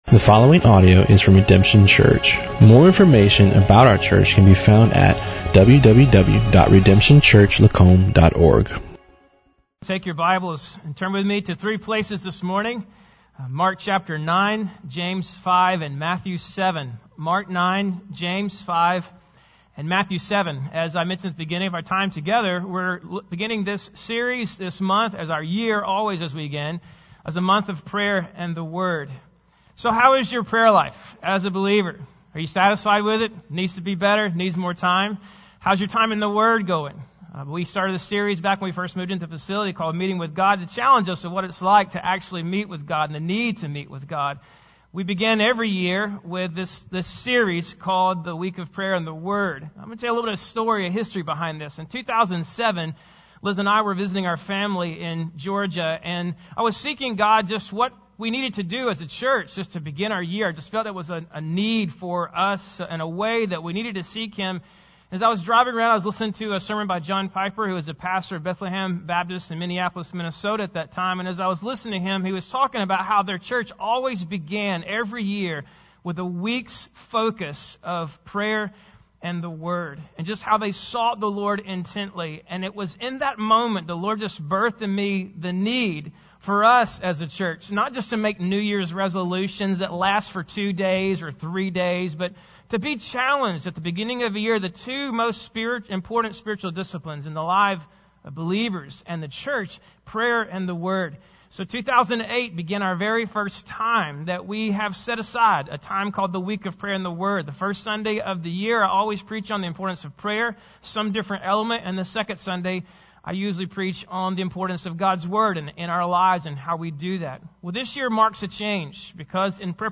Bible Text: Mark 9, James 5, Matthew 7 | Preacher: